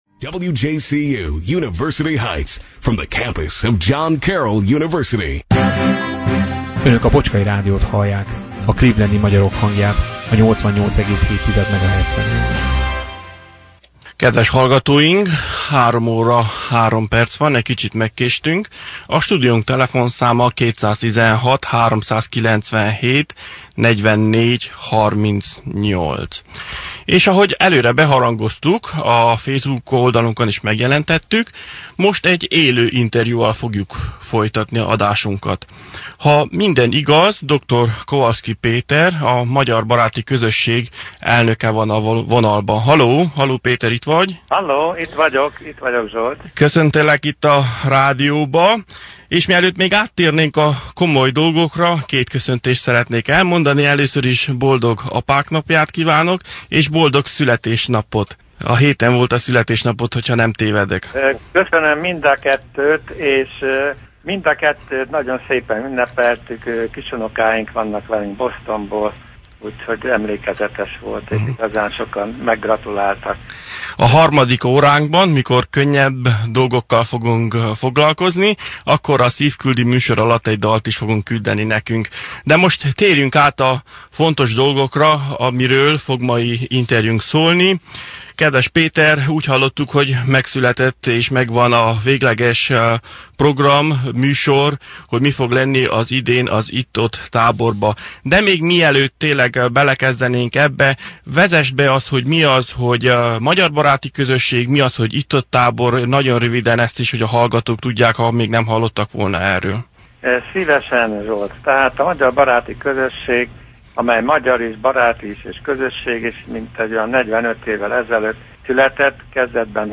A múlt vasárnapi adásunk alatt élőben készült interjúban